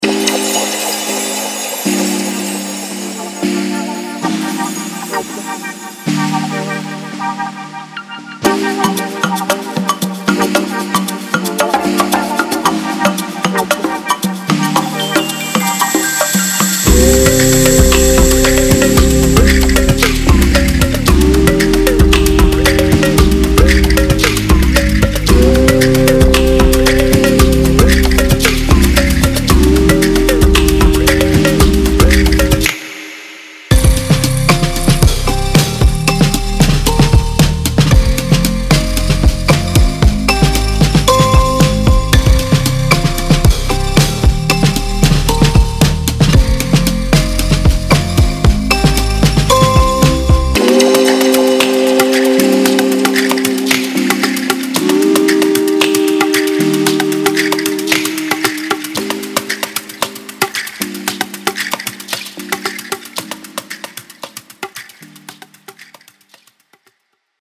Percussion and Drums are the main elements of Afrobeats. From the original sound of Lagos this pack is essential for producers desiring that special Afrobeat touch, The sample pack focuses on Afrobeat Loops, shakers, Drum fills and Modern Afro one shots.